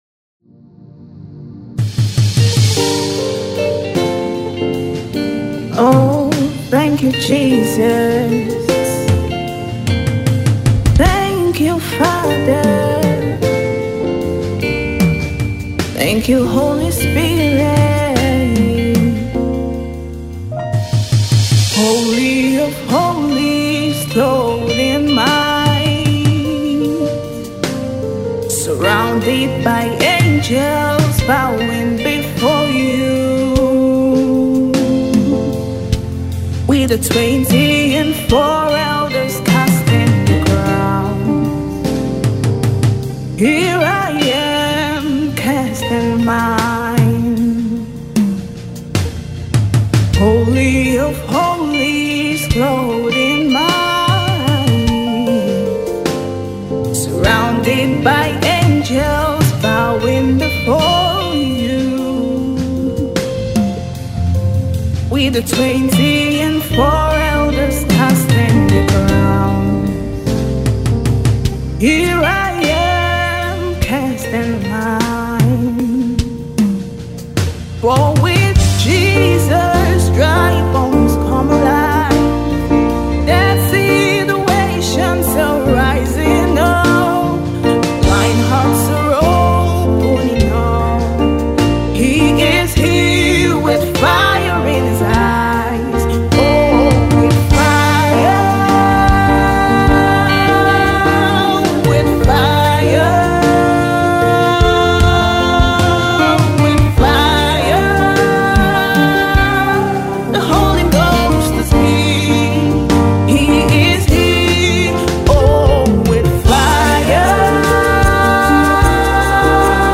Nigeria Gospel Music Minister